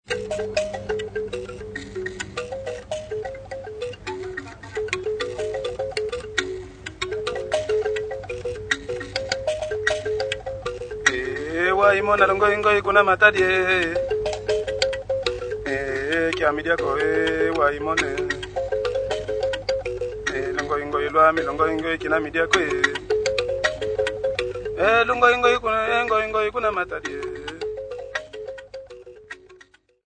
Folk music--Africa
Field recordings
Africa Congo Elizabethville, Lubumbashi f-cg
sound recording-musical
Nostaligic song about a husband not wanting to eat because his wife is at Matadi accompanied by mbira and msambi.
30ips pancake reel